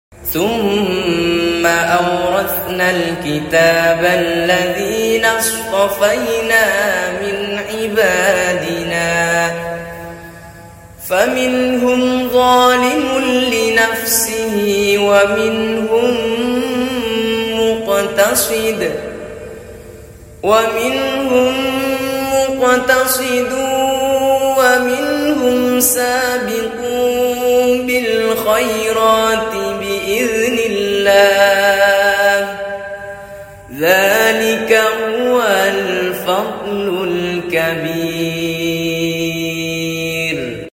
Murottal Menyentu hati, Surah Fatir sound effects free download